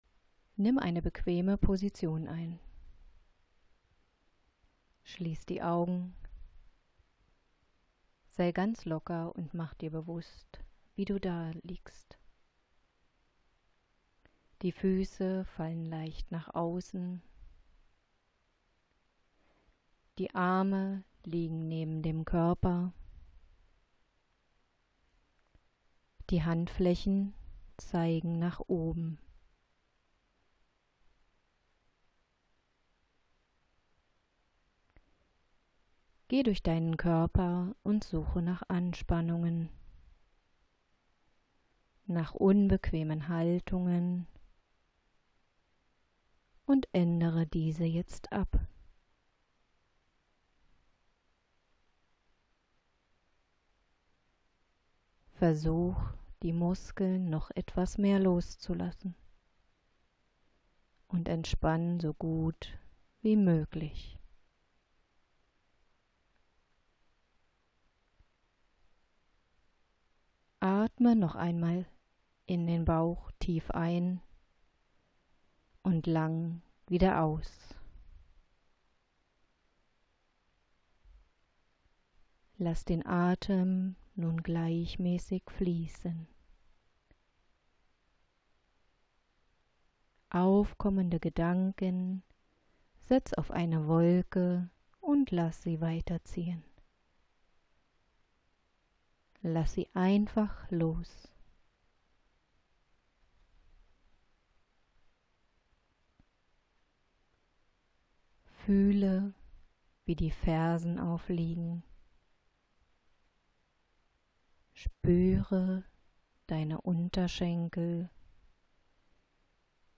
Stimmprobe
Gerade bei wortbetonten Entspannungsmethoden wie PMR oder Autogenem Training ist es wichtig, dass einem die Stimme des Trainers sympathisch ist, dass man mit der Stimme „kann“. Damit Sie sich vorab einen Eindruck davon machen können, was Sie stimmlich in meinen Kursen erwartet, biete ich Ihnen hier die Möglichkeit, vorab eine kleine Stimmprobe zu hören.